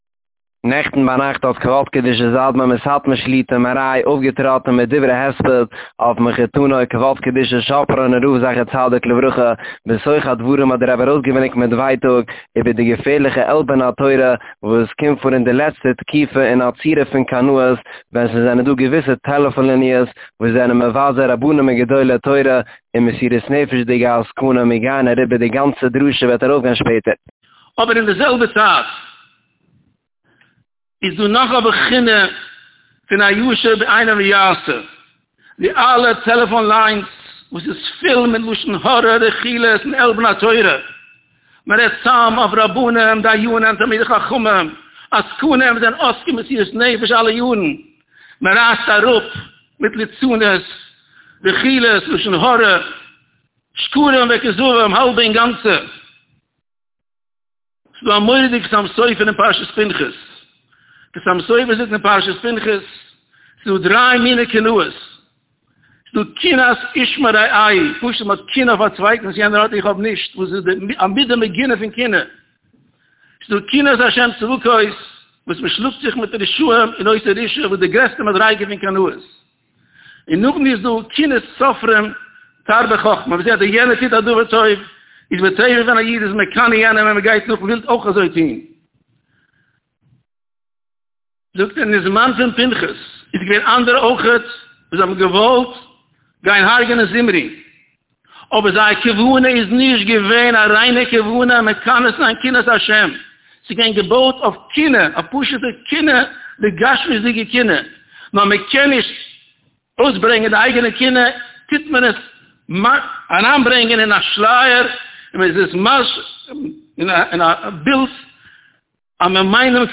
נעכטן ביינאכט איז פארגעקומען א גרויסארטיגע 'עצרת הספד' לזכרו הבהיר פון כ"ק הגה"צ גאב"ד שאפראן זצ"ל ווי צווישן אנדערע מספידים האט אויפגעטראטן מחותנו יבדלחט"א כ"ק מרן אדמו"ר מסאטמאר שליט"א. דער רבי שליט"א האט א א לענגערע צייט ארומגערעדט מילי דהספידא ודברי הערכה אויף דעם גרויסן נפטר און באוויינט די גרויסע אבידה, וועלכע איז געווען אין די בחי' פון א קרבן ציבור אין דעם שווערן עת צרה.
דאן האט דער רבי שליט"א אויפגעשטורעמט דאס זאל מיט דברים חוצבים להבות אש און ארויסגעברענגט מיט ווייטאג, איבער דער געפערליכער עלבון התורה, וואס ווערט אפגעטון אין די לעצטע תקופה מיט א צורה פון 'קנאות', ווען געוויסע טעלעפון ליניע'ס וואס זענען מבזה רבנים וגדולי תורה און מסינ"פ'דיגע עסקנים, אויף א פארנעם וואס איז נישט געזען געווארן זיינס גלייכן ביי קיין שום מלחמת ה' אין אלע דורות.